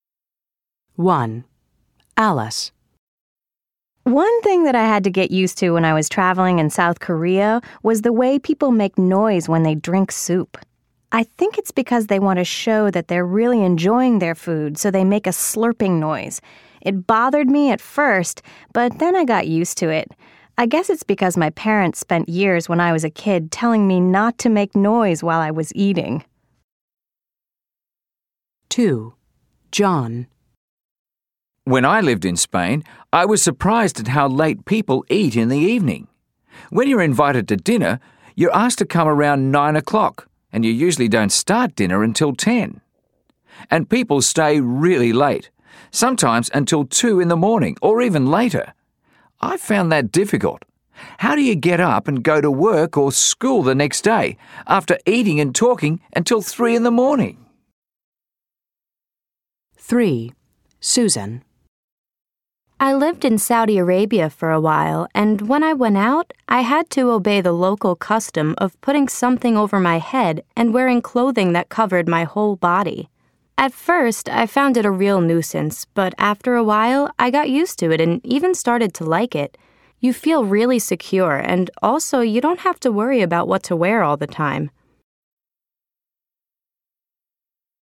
Listen to three people describe customs abroad: